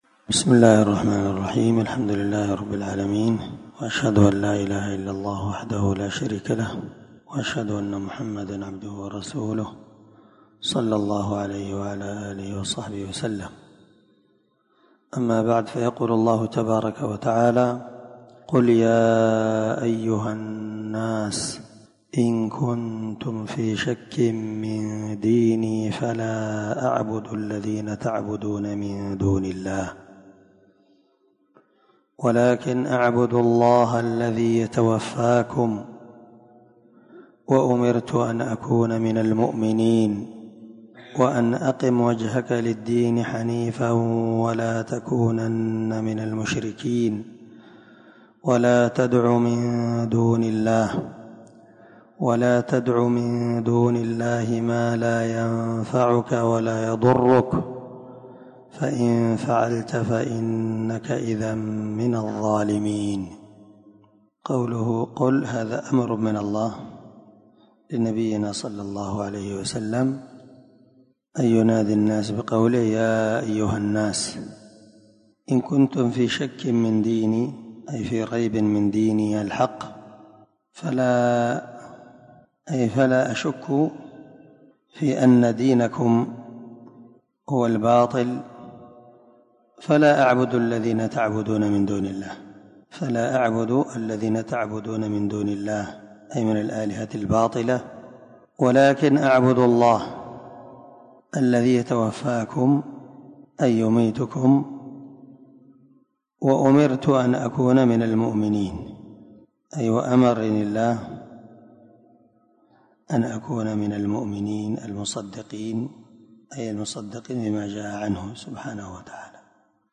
618الدرس 34 تفسير آية ( 104- 106) من سورة يونس من تفسير القران الكريم مع قراءة لتفسير السعدي